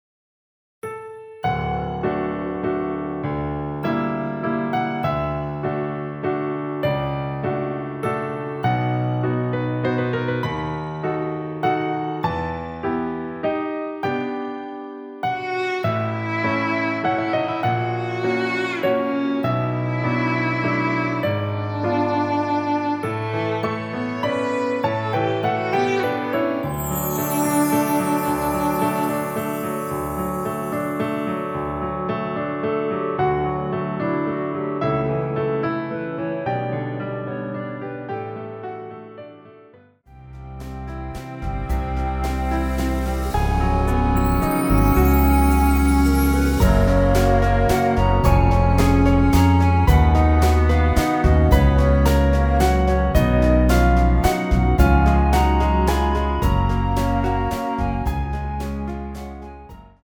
원키에서(-4)내린 멜로디 포함된 MR입니다.
앞부분30초, 뒷부분30초씩 편집해서 올려 드리고 있습니다.